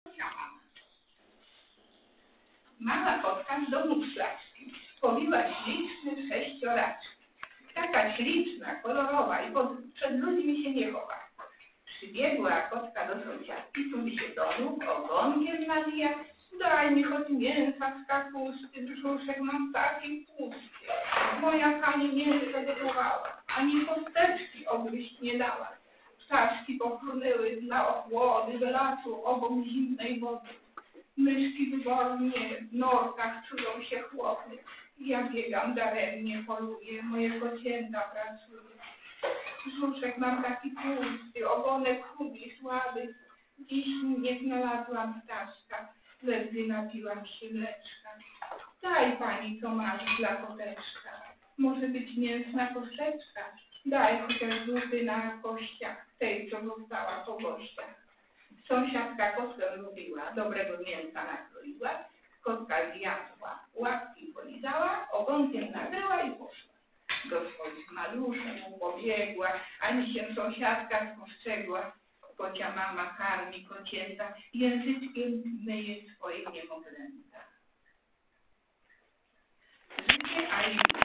O godzinie 10. rozpoczęło się spotkanie członków i sympatyków Grupy Literackiej "Gronie".
(przrepraszamy za zakłócenia i niezbyt dobrą jakość nagrania)